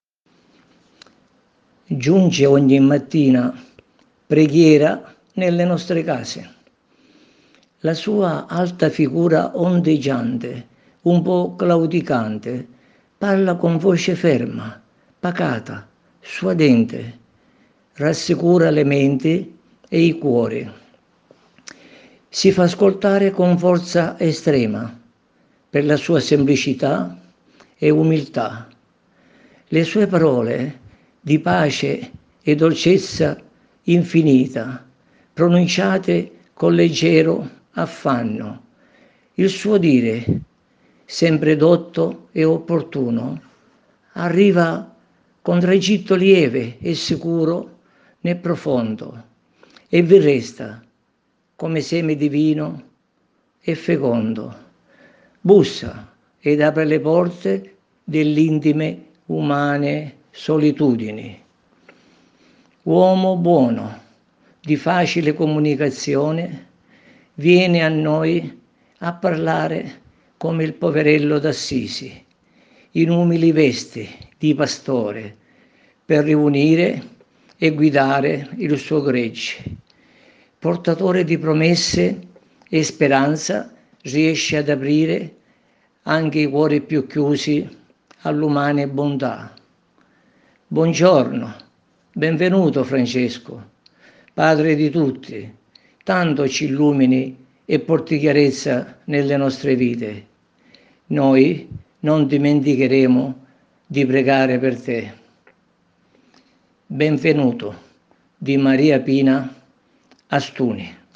interpreta la poesia